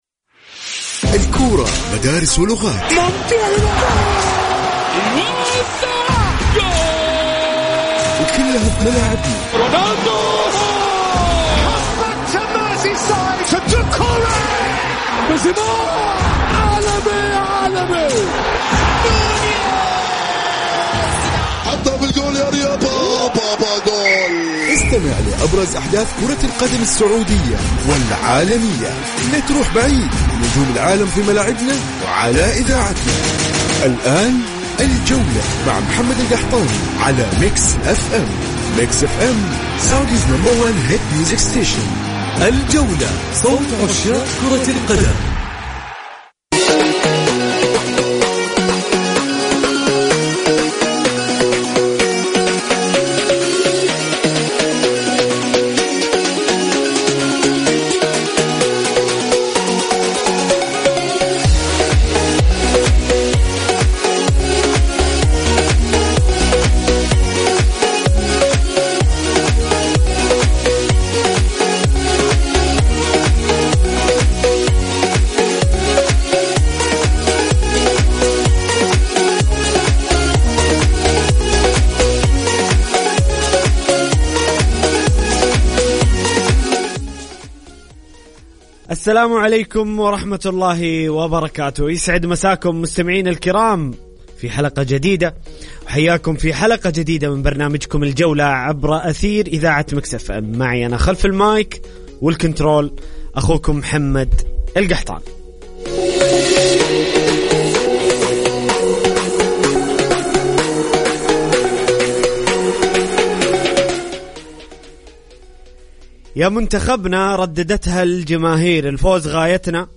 A local sports program highlights the most important concerns of the sports center and reviews the most prominent sports journalism and social networking sites .. Add the most prominent analysts and sports stars and officials .. Sunday to Thursday at 6 pm to seven